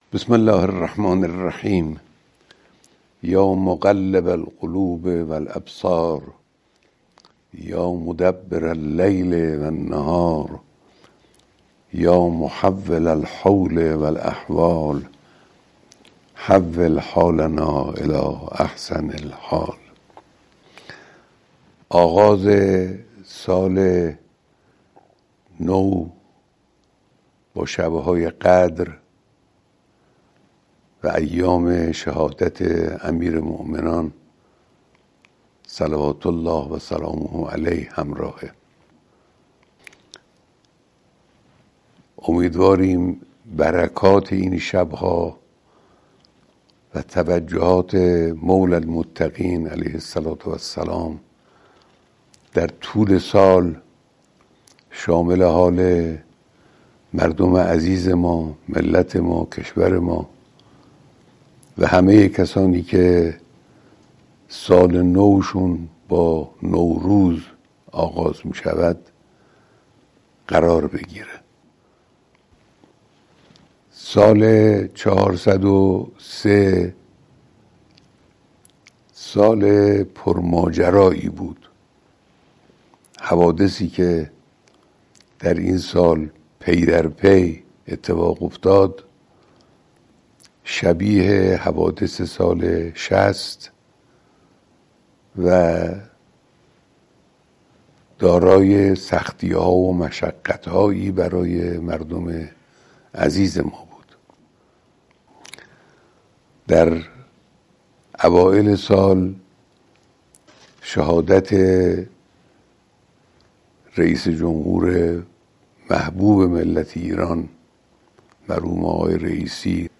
حضرت آیت‌الله خامنه‌ای، رهبر انقلاب اسلامی، در پیامی به مناسبت آغاز سال ۱۴۰۴، سال جدید را سال «سرمایه‌گذاری برای تولید» نام‌گذاری کردند.